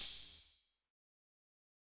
Open Hat (6).wav